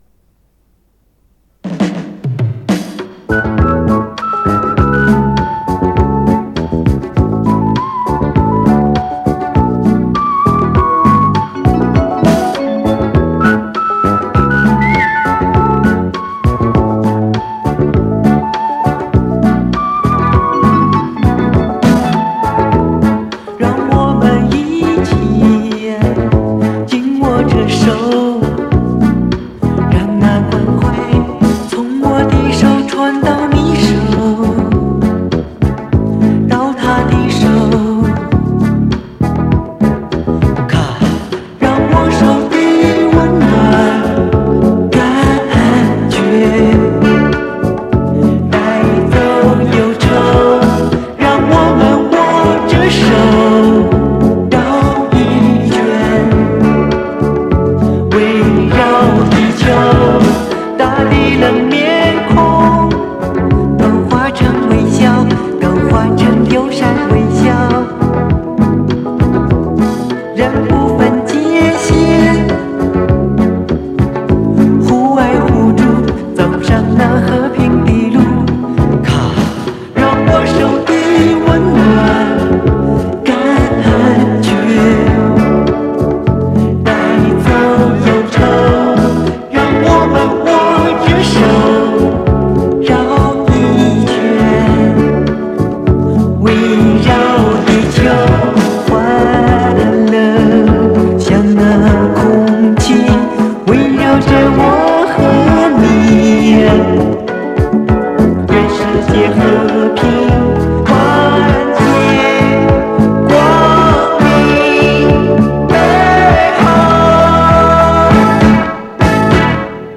磁带数字化：2023-02-11